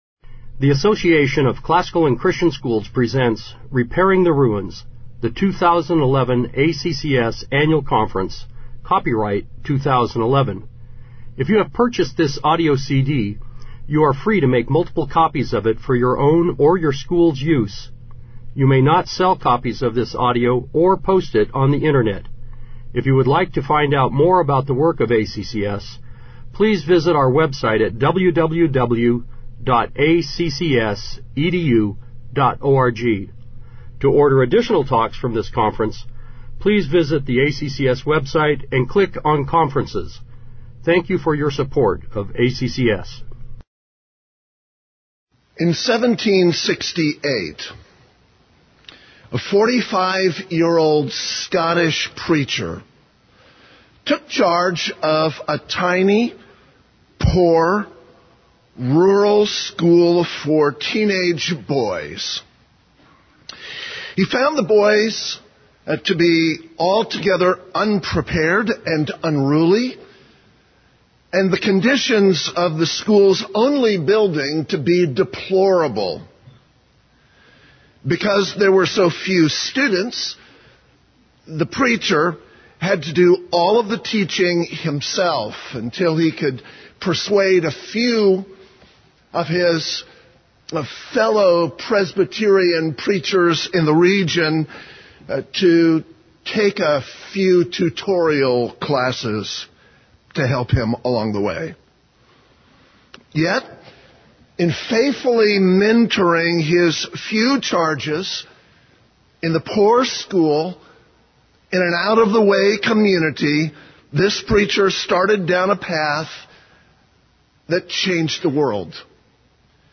2011 Plenary Talk | 0:36:21 | All Grade Levels, Leadership & Strategic